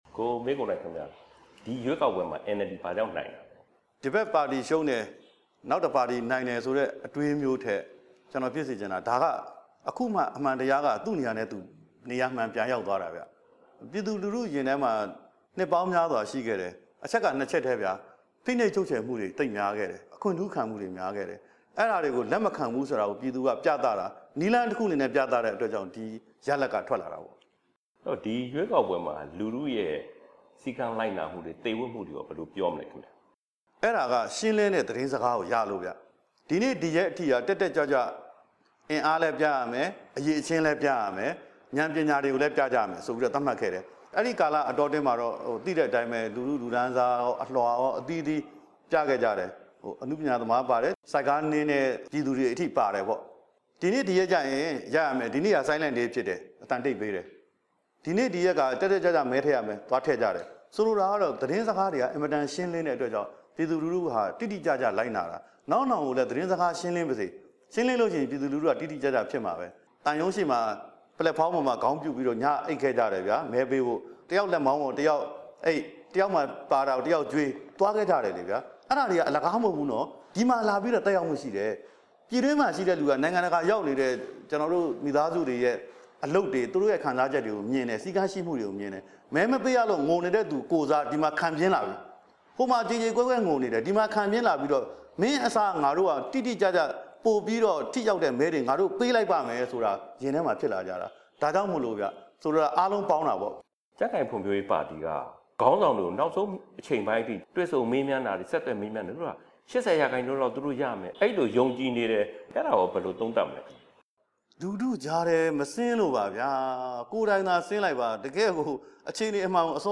၈၈ မျိုးဆက် ခေါင်းဆောင် ကိုမင်းကိုနိုင်နဲ့ မေးမြန်းချက် အပိုင်း ( ၁ )